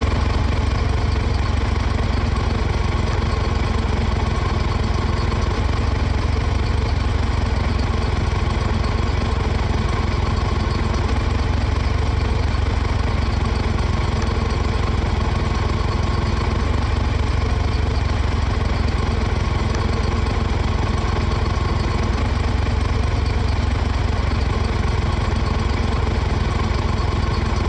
MotorLaeuftLoop.wav